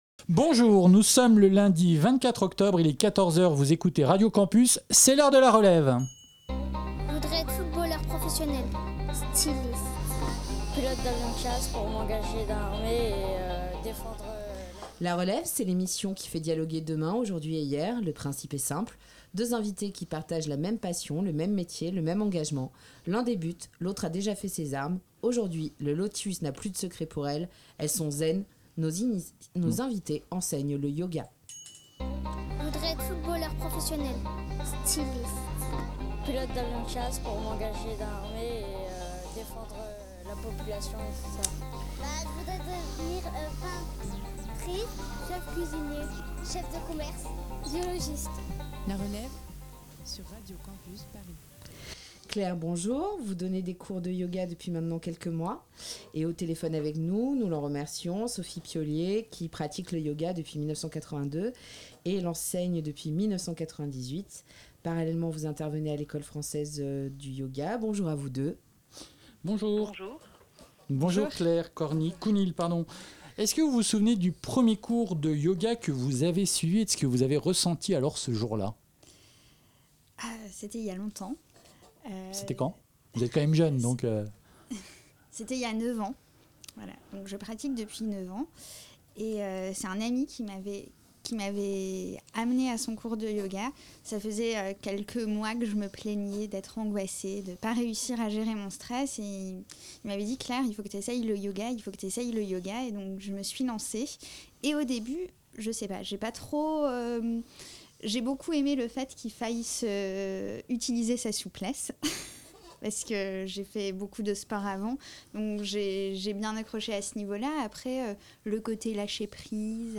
Nous n'avons pas fait toute l'émission en position du lotus, mais nous avons interrogé la posture, en recevant deux professeurs de yoga.
Entretien